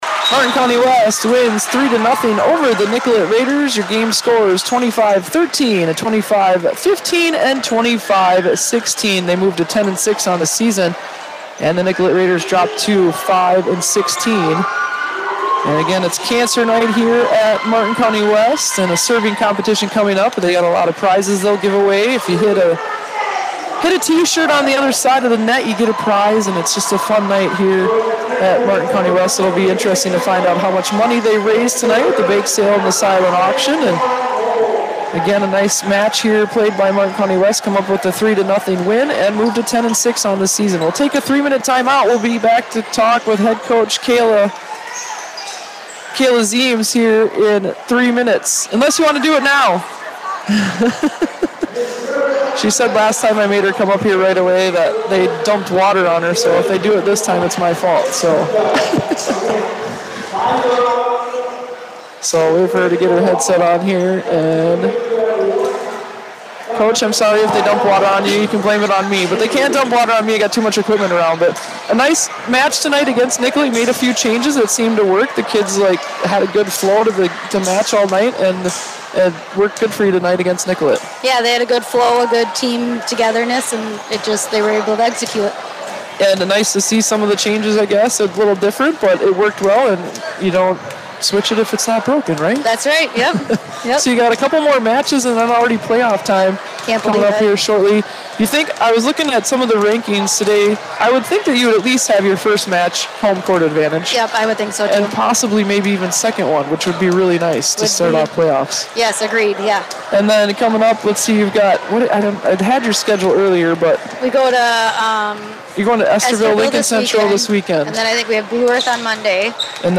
FULL POSTGAME SHOW The 10-6 Mavericks are headed to Estherville, Iowa for the ELC Varsity Volleyball Tournament this weekend.